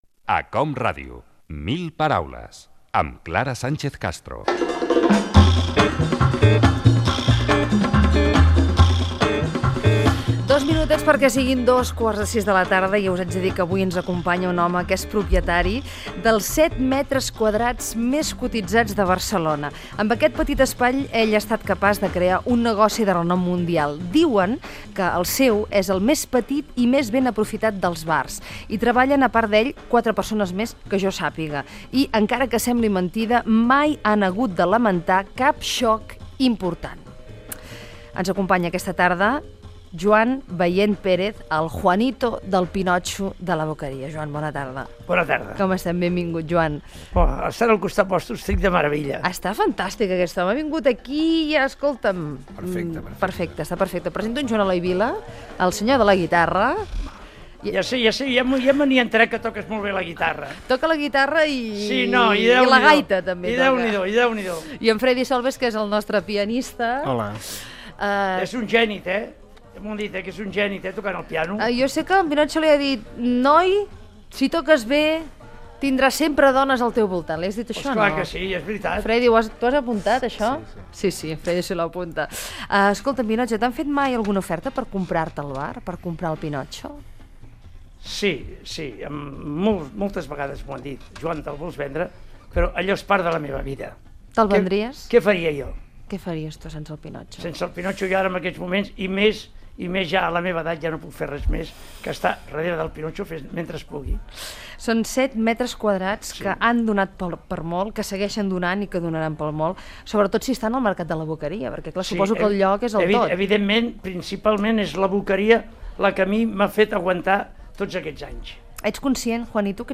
Identificació del programa, hora, presentació i entrevista
Entreteniment